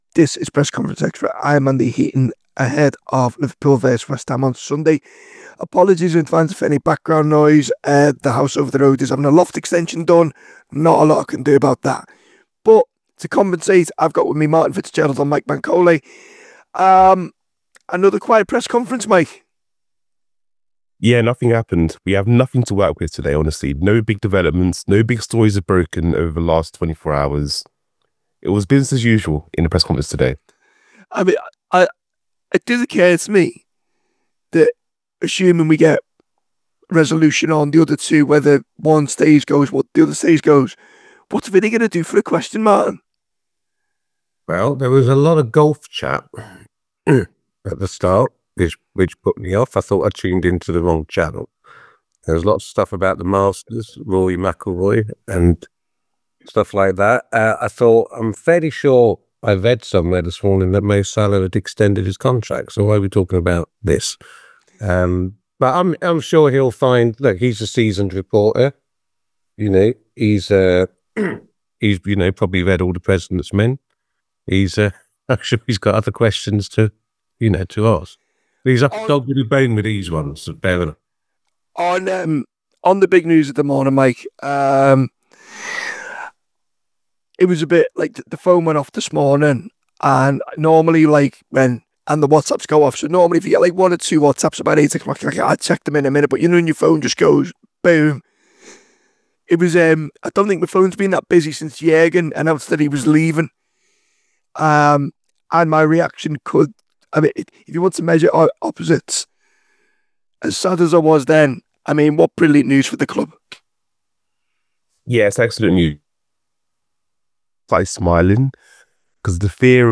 Below is a clip from the show – subscribe for more on the Liverpool v West Ham press conference…